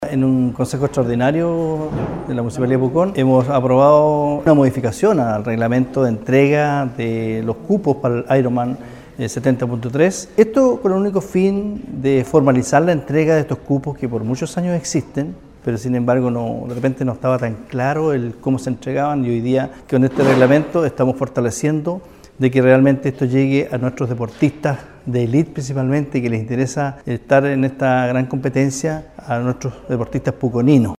Julio-Inzunza-concejal-explica-lo-realizado-por-el-estamento-colegiado-.mp3